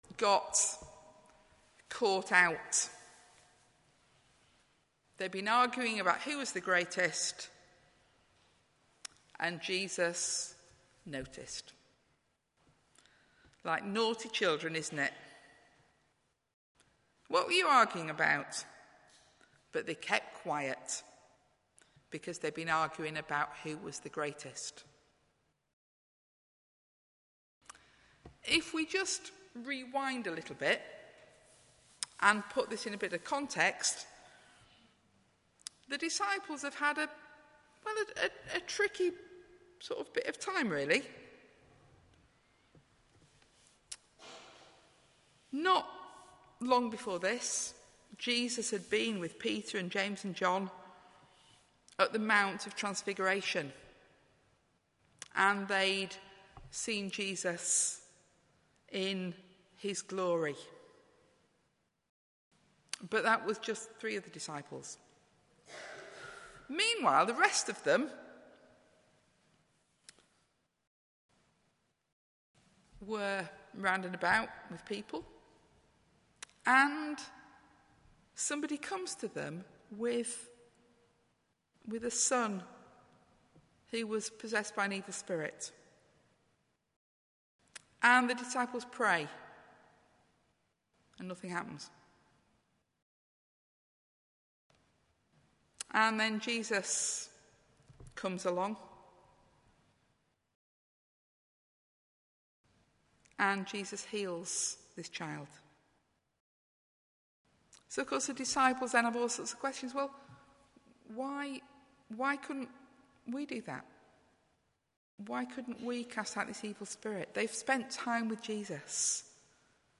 Talk
in 10:30 Morning Worship, St John's service